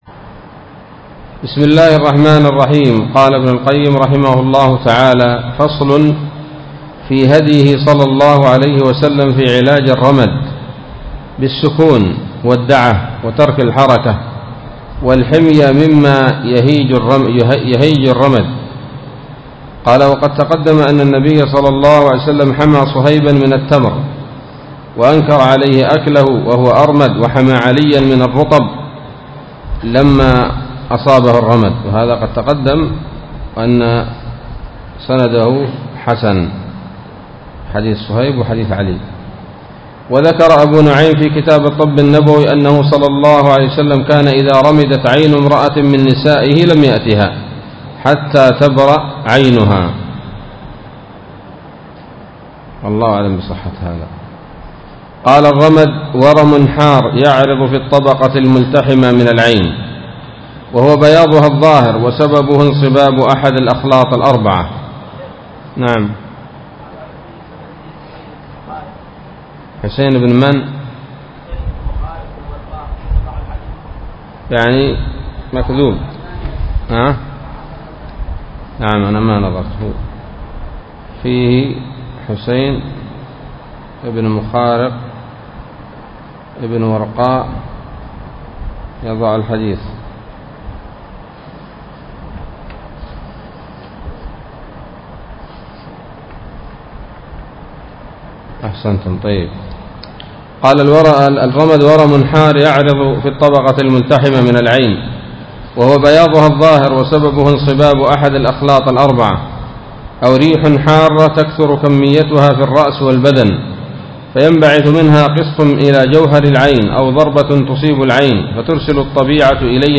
الدرس التاسع والعشرون من كتاب الطب النبوي لابن القيم